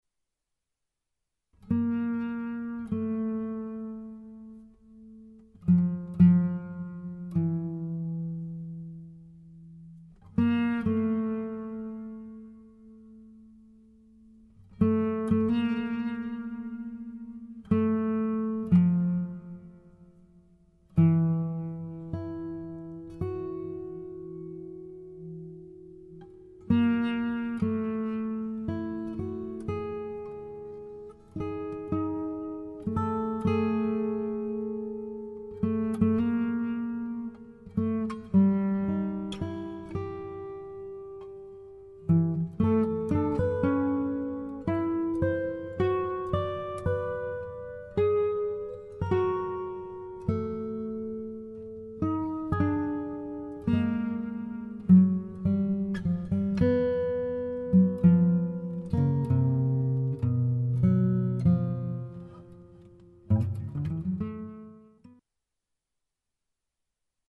for 6-string and fretless classical guitar duet